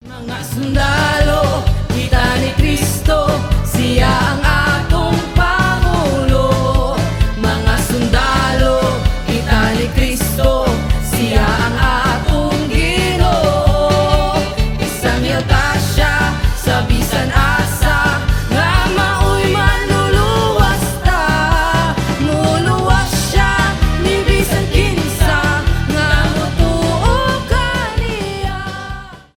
религиозные , христианские , зарубежные , филиппинские , рок